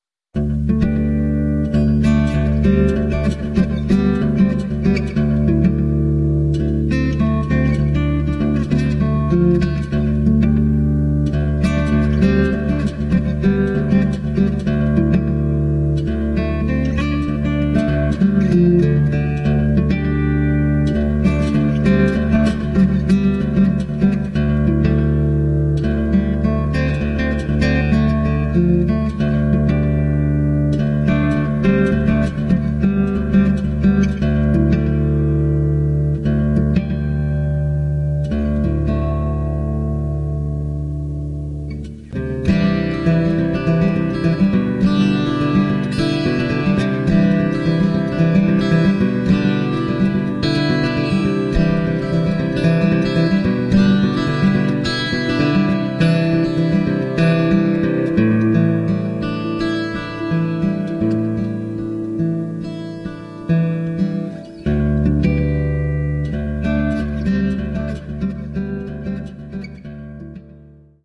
Basically, a warm, very gentle piece of guitar playing.